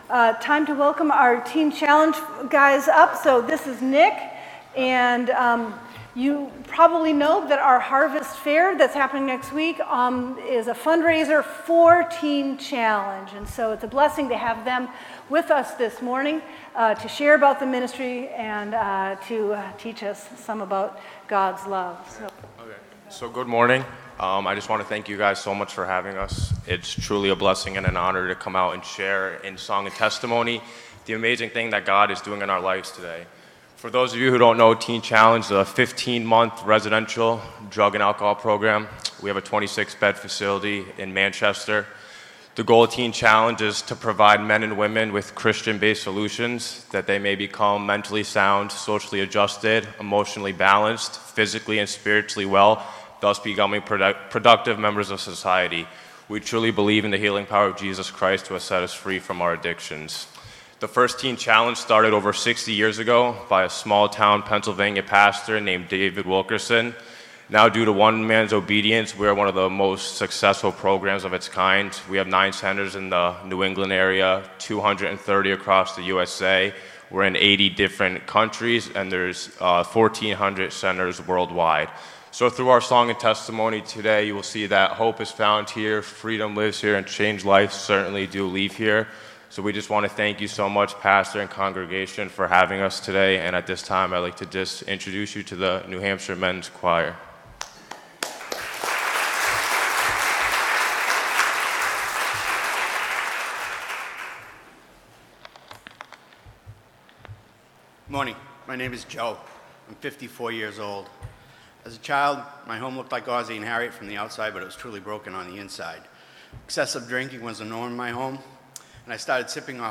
Teen Challenge Sermon
Teen_Challenge_Sermon.mp3